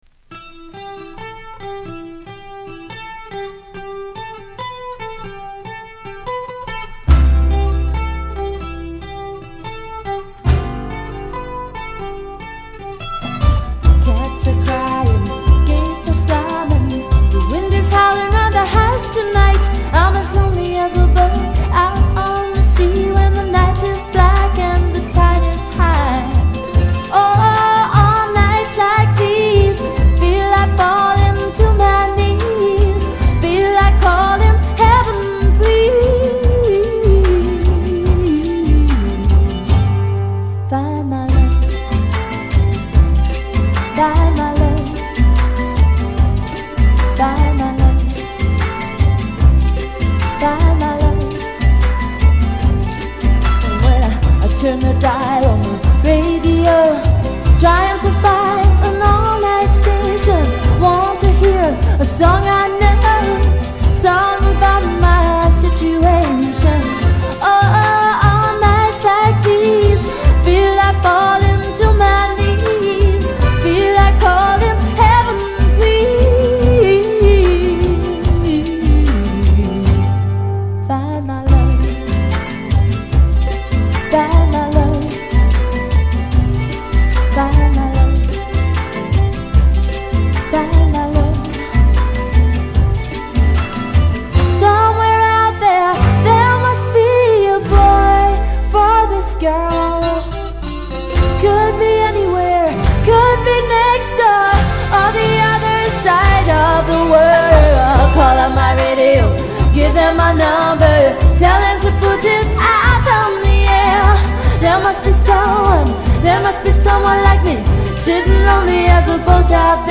心地よいスウィング感と透明感溢れる柔らかなサウンドに釘付けです。